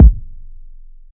Kick (17).wav